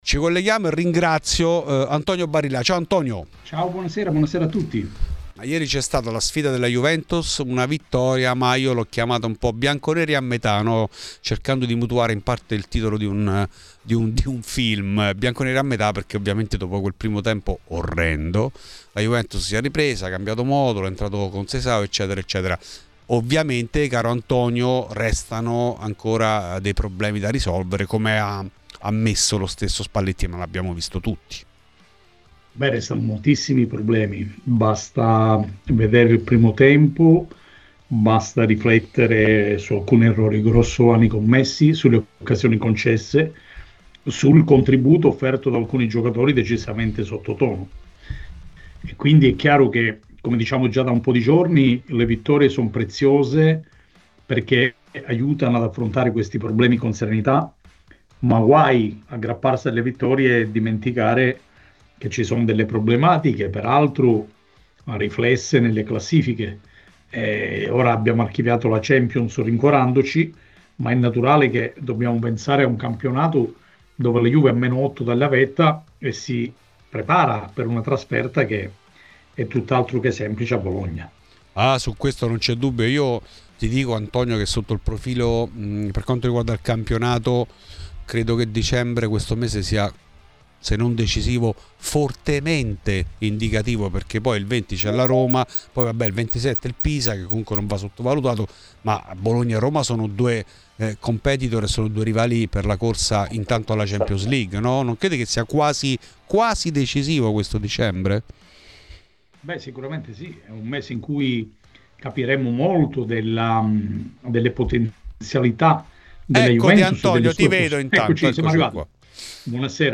è stato ospite di Radio Bianconera , durante Fuori di Juve , per commentare le vicende della squadra allenata da Luciano Spalletti